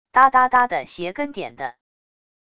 嗒嗒嗒的鞋跟点地wav音频